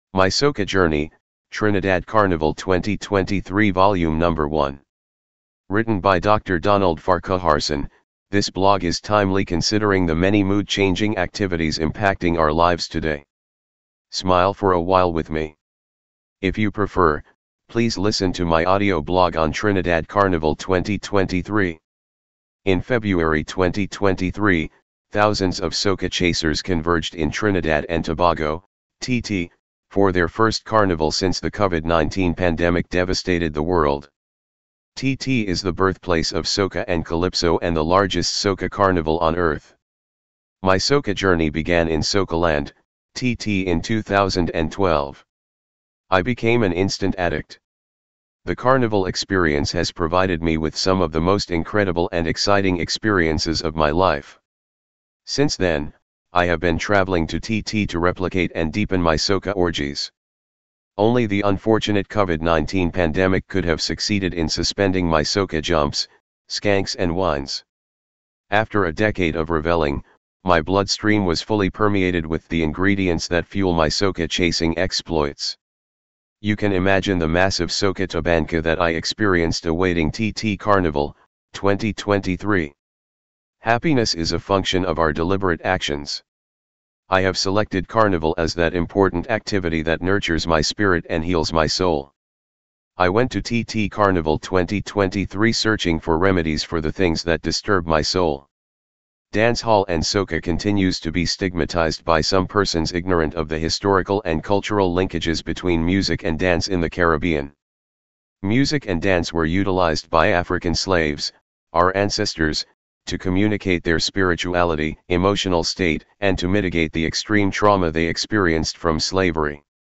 If you prefer, please listen to my Audio Blog on Trinidad Carnival 2023.